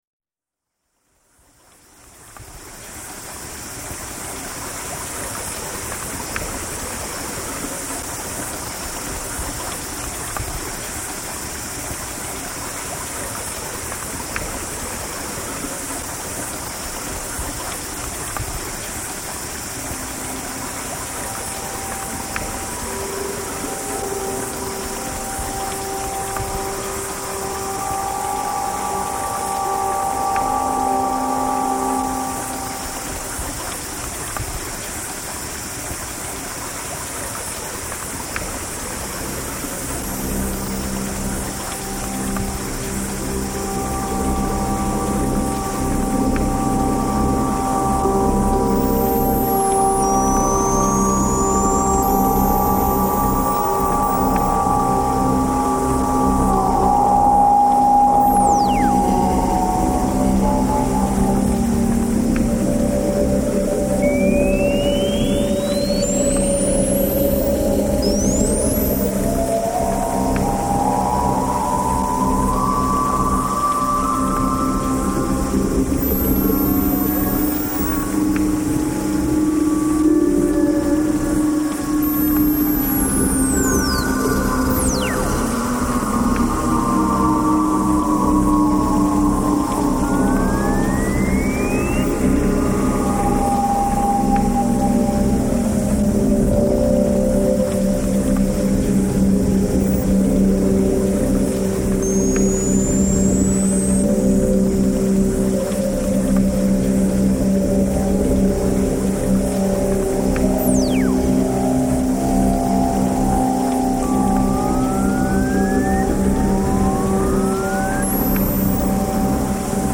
Morere Hot Springs reimagined